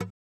ui_generico.wav